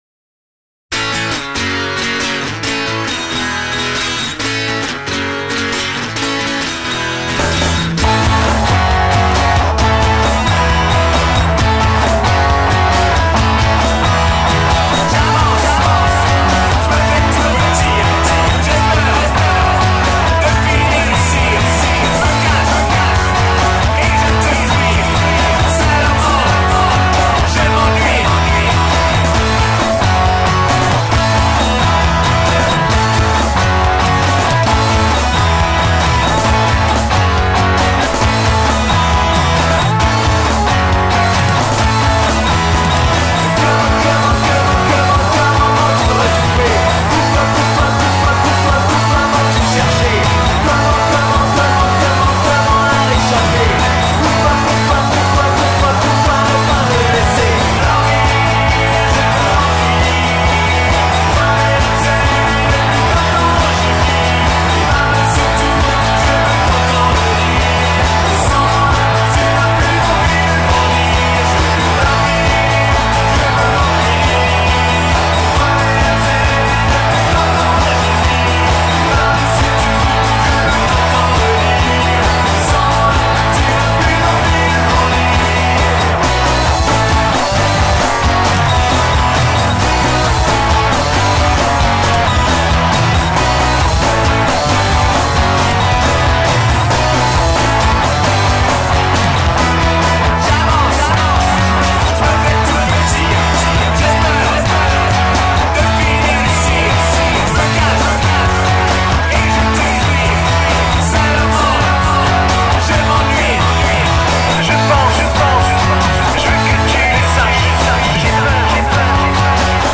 Avis enregistrement/mixage pop/rock - forum Prise de Son & Mixage - Audiofanzine
Je me suis récemment monté un petit chez moi pour enregistrer les compos de mon groupe, le matos dont je dispose:
- AKG C3000B pour les voix
- Shure SM57 pour les prises guitares
L'ampli guitare: Fender Blues Junior (guitare lead)
et le simulateur Vox Tonelab (guitare accoustique + basse)
Les prises de son sont normalement bonnes, pas de clip, un compresseur peut etre un peu fort, mais j'apprends :)
Perso, je trouve que les grattes sont trop en avant.... la batterie semble trop loingtaine...
Les voix ne sont pas assez compréhensibles.
Sinon le décalage de l'intro est fait exprès, c'est 2 prises différentes, dont j'ai décalé l'une d'elle pour l'intro de 20ms, et l'entrée batterie est aussi un peu décalé mais ca c'est fait a l'enregistrement, mais aussi exprès.
Bien la compo! excellent la voix (et son traitement délayé-petit relent Indochinesque sur le refrain...)